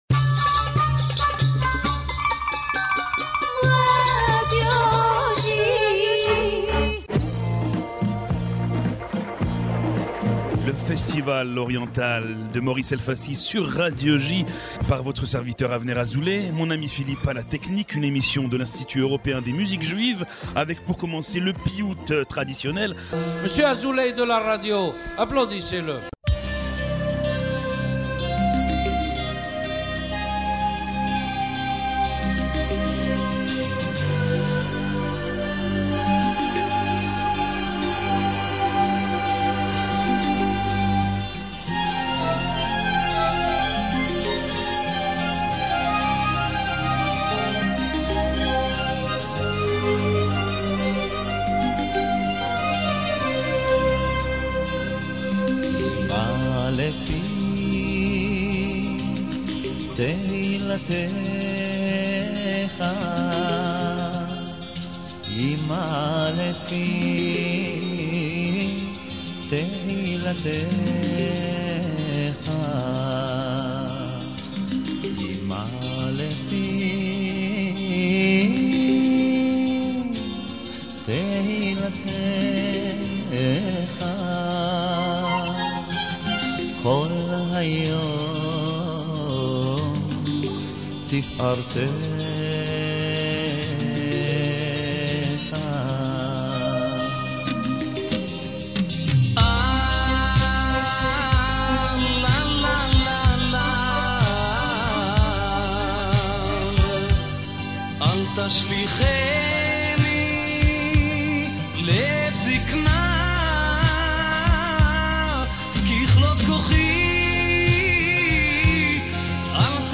« Le festival oriental » est une émission de l’Institut Européen des Musiques Juives entièrement dédiée à la musique orientale.